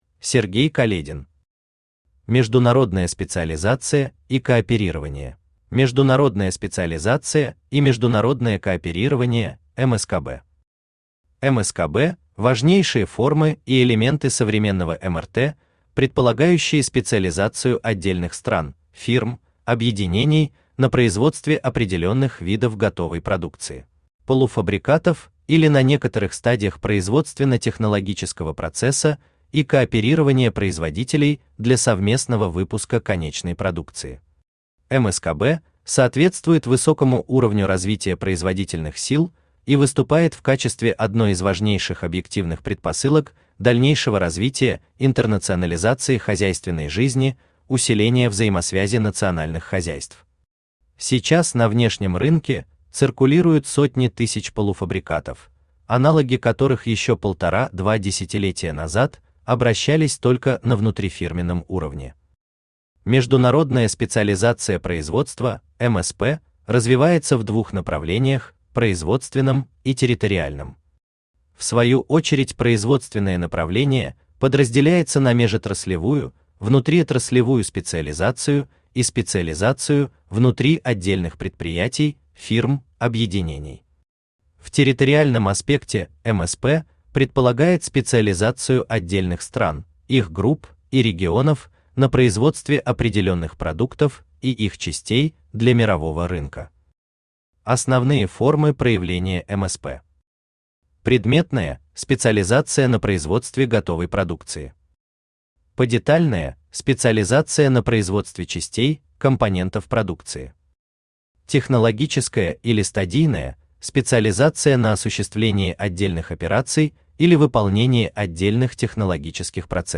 Аудиокнига Международная специализация и кооперирование | Библиотека аудиокниг
Aудиокнига Международная специализация и кооперирование Автор Сергей Каледин Читает аудиокнигу Авточтец ЛитРес.